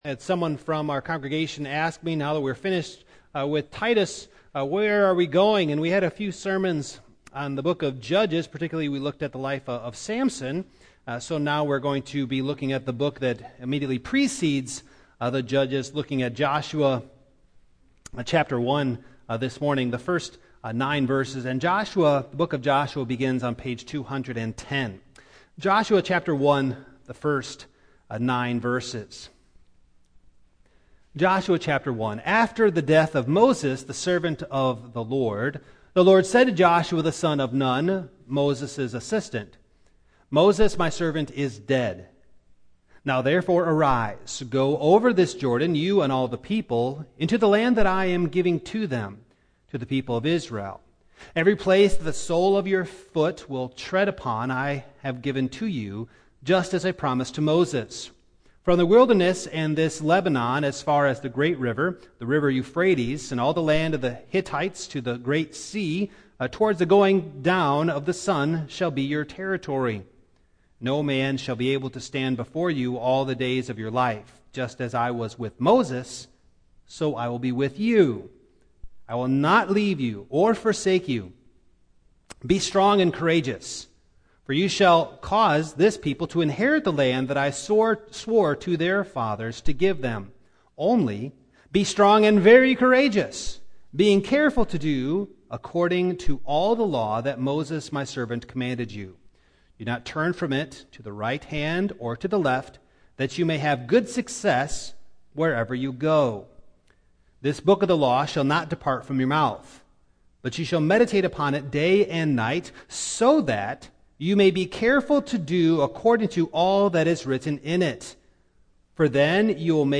The Book of Joshua Passage: Joshua 1:1-9 Service Type: Evening Download Files Notes « Finding True Faith Which God is Revealed?